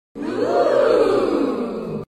Crowd Suprise OOH